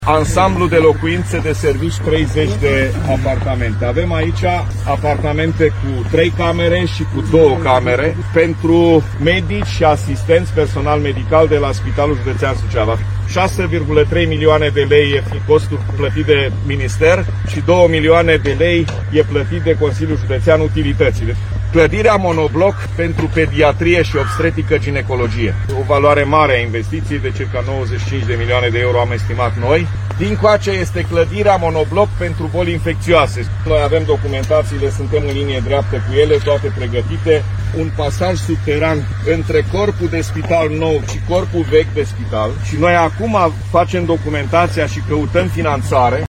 Președintele Consiliului Județean GHEORGHE FLUTUR a prezentat succint aceste obiective.